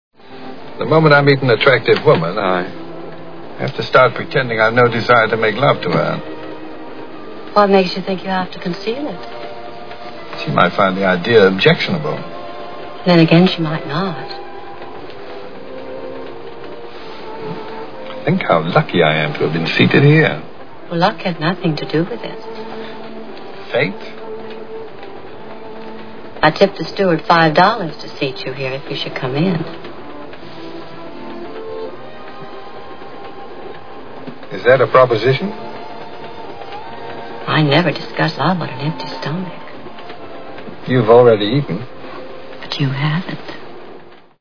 North by Northwest Movie Sound Bites